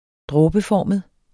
Udtale [ ˈdʁɔːbəˌfɒˀməð ]